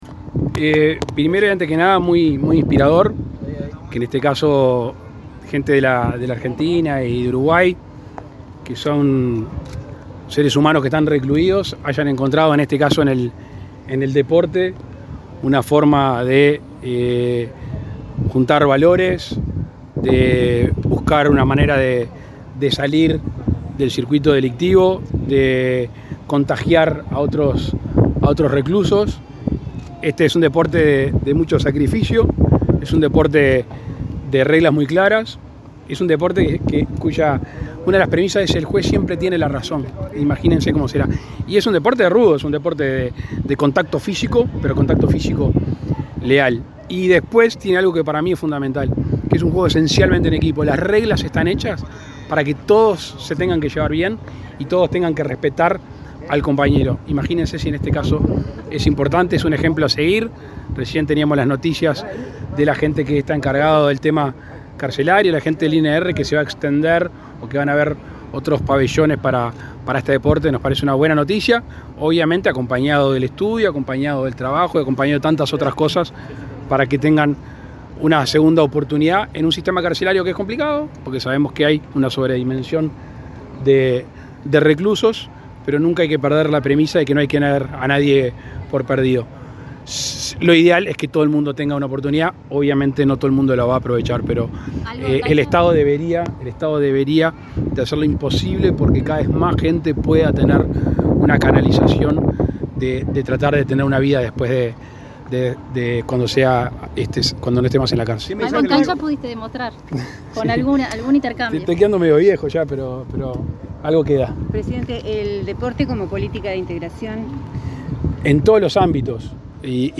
Declaraciones del presidente Lacalle Pou a la prensa
Luego, dialogó con la prensa.